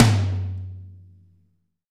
Index of /90_sSampleCDs/Roland L-CD701/KIT_Drum Kits 3/KIT_West Coast
TOM AC.TOM06.wav